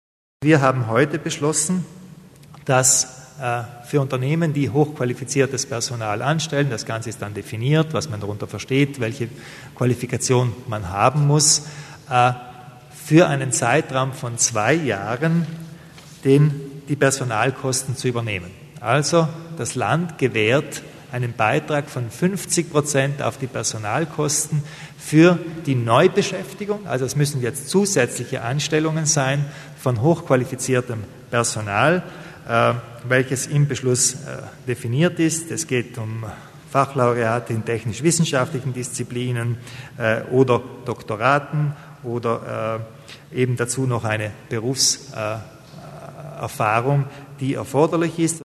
Landeshauptmann Kompatscher zu den Maßnahmen auf dem Arbeitsmarkt